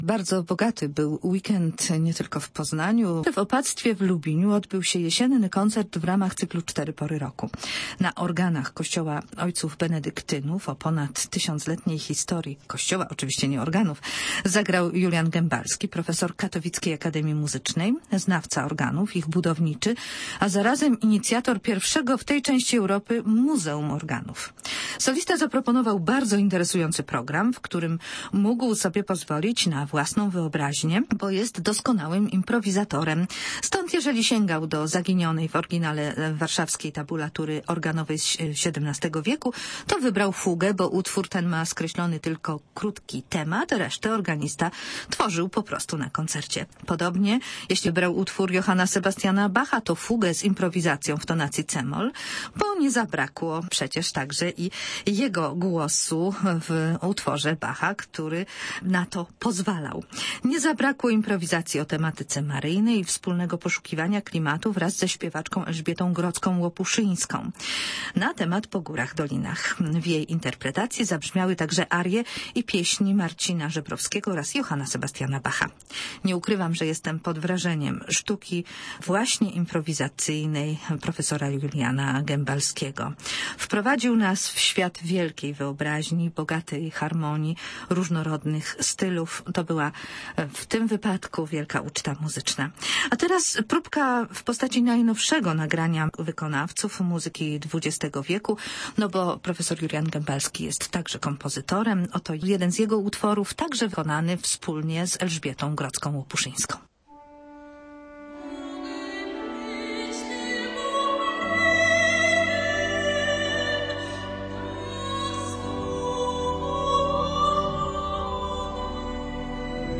W Opactwie Ojców Benedyktynów w Lubiniu odbył się kolejny koncert z cyklu „Cztery Pory Roku”.